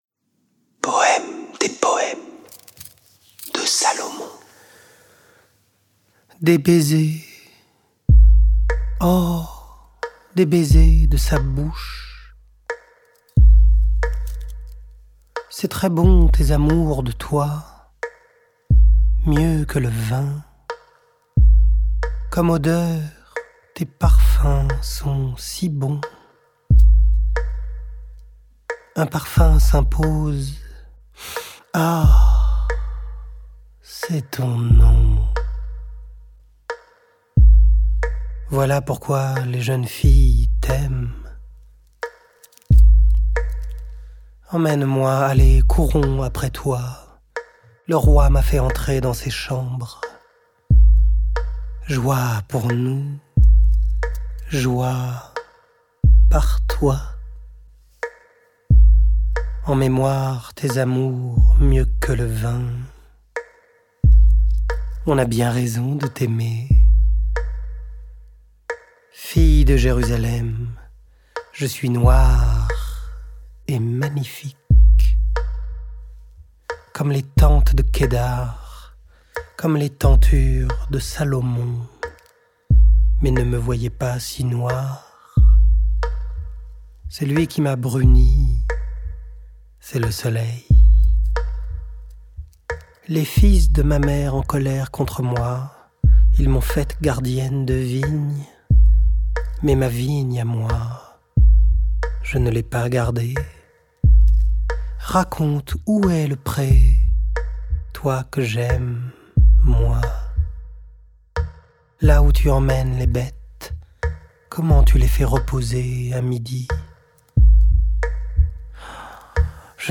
La Bible des écrivains Une traversée musicale et immersive
Le défi : incarner seul l'intégralité des récits.
S'il y a un discours, je l'adresse ; s'il y a un cantique, je chante ; s'il y a un poème, j'y plonge ; s'il y a une prière, je la cherche ; s'il y a un éloge, une prophétie, un oracle, une lettre... je m'adapte.
Vous devez absolument mettre un casque audio : nous sommes face à face sous la terre, assis côte-à-côte sur la barque, puis ensemble dans la grotte. Avec une réelle sensation d'immersion.